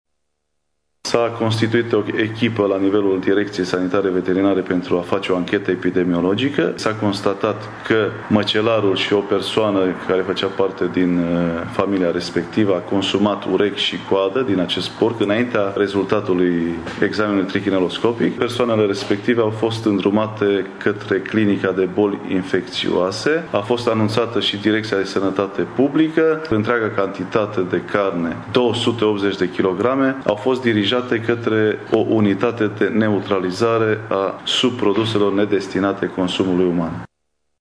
Anunţul a fost făcut azi de Şeful Direcţiei Sanitar Veterinare şi pentru Siguranţa Alimentelor Mureş, dr. Vasile Liviu Oprea: